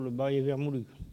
Localisation Sallertaine
Catégorie Locution